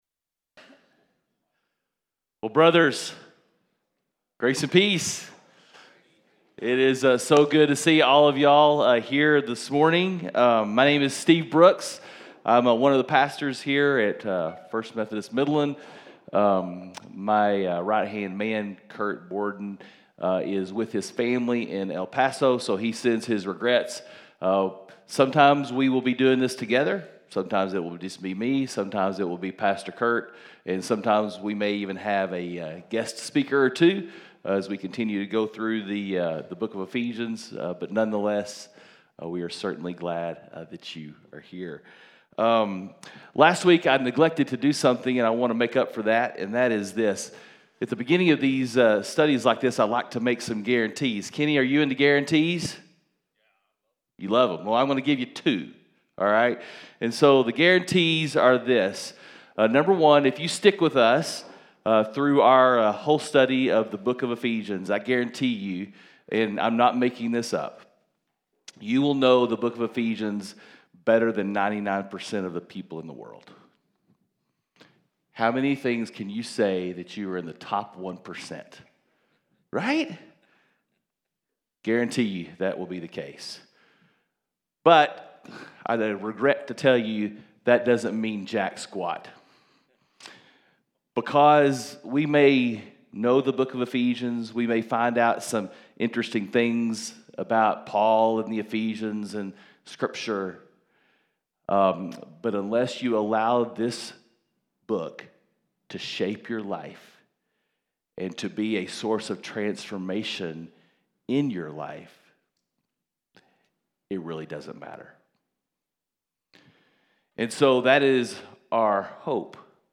Men’s Breakfast Bible Study 7/21/20